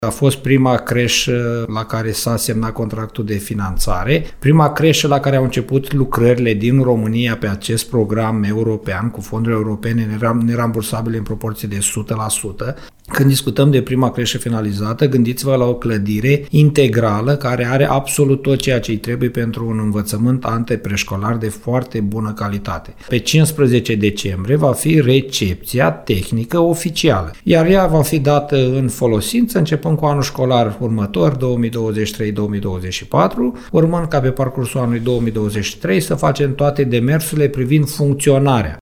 Primarul CĂTĂLIN COMAN a reamintit, la postul nostru de radio, că viitoarea creșă va avea o capacitate de 90 locuri.